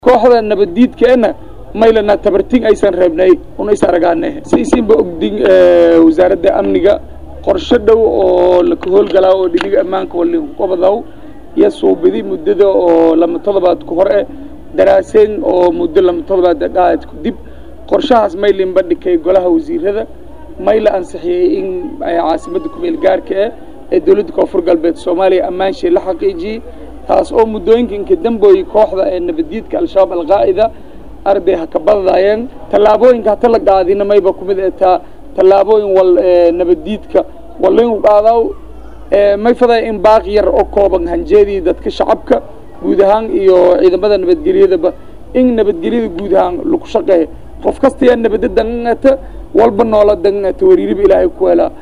Waxa uu Cabdifitaax Geeseey hadalkaan ka sheegay Shir jaraa’id uu ku qabtay Magaalada Baydhabo isagoo tibaaxay in Al Shabaab iyo iyaga la arki doono cida dagaalka ku guuleysata.